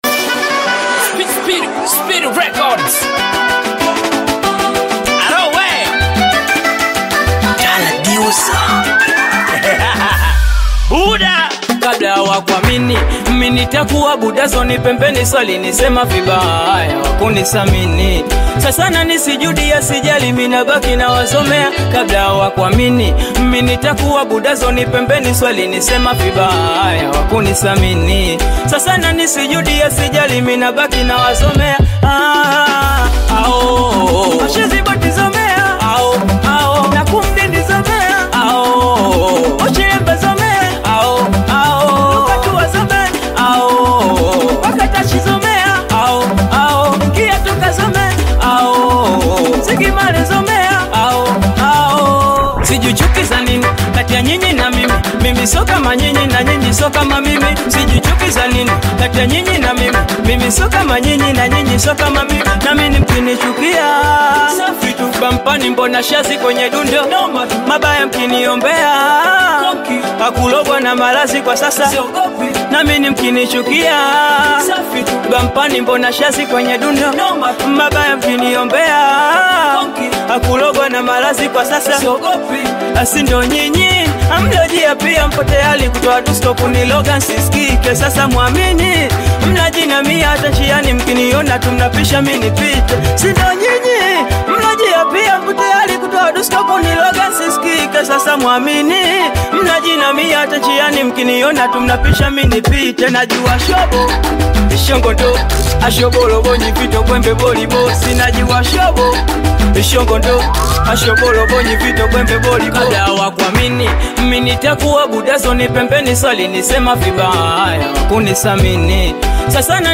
Singeli music track
Tanzanian Bongo Flava singeli artist